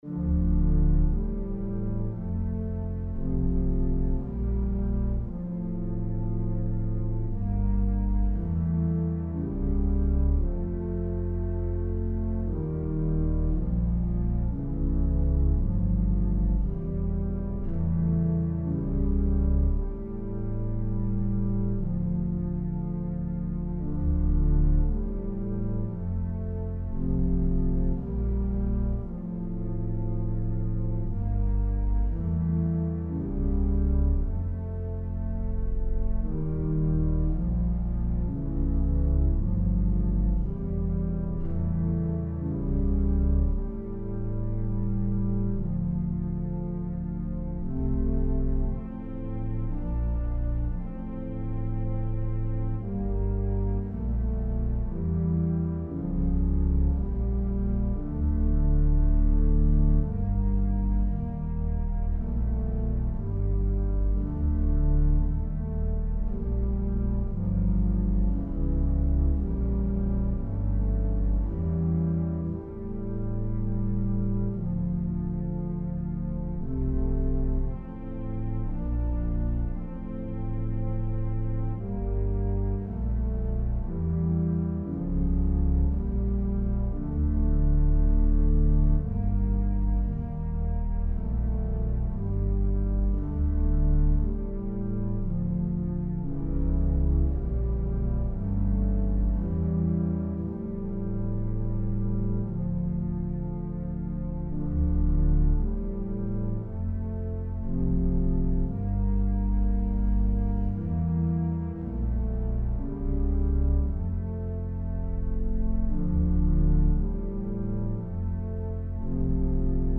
For the Fifth Decade of prayers of The Chaplet of the Divine Mercy, click the ▶ button to listen to a polyphonic organ setting, implicitly in a minor mode, of the Gregorian Plainchant Ave Verum Corpus, or play the music in a New Window